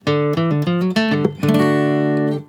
acoustic_guitar.wav